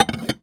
R - Foley 35.wav